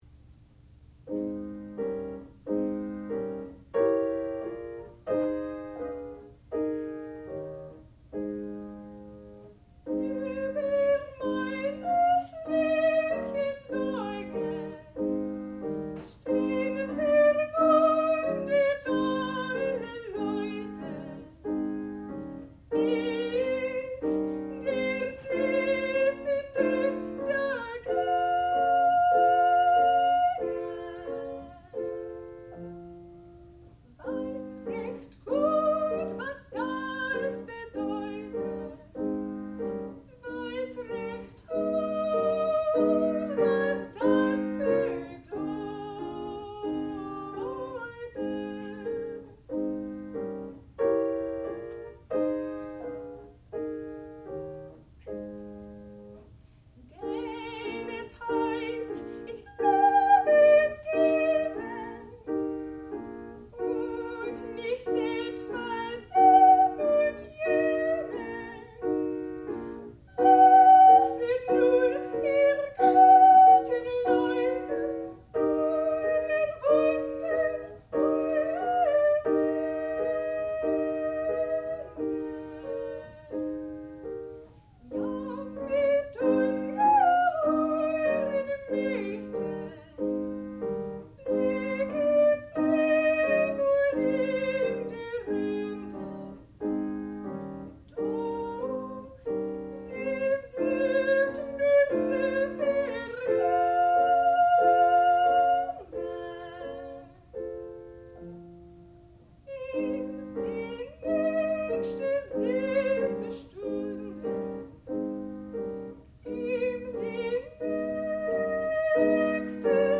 sound recording-musical
classical music
piano
Student Recital
soprano